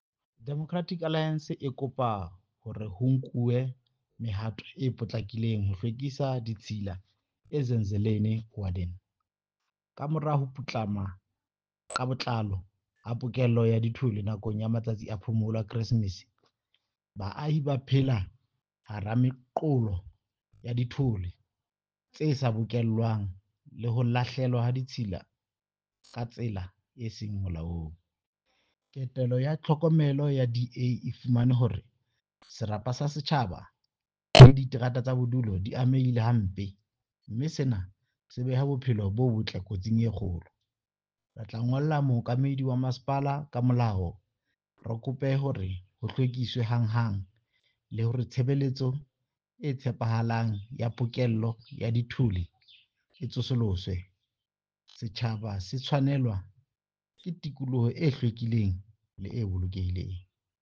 Sesotho soundbite by Cllr Diphapang Mofokeng.